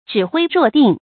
指揮若定 注音： ㄓㄧˇ ㄏㄨㄟ ㄖㄨㄛˋ ㄉㄧㄥˋ 讀音讀法： 意思解釋： 指揮調度；似有定局（若：好像；定：定局）。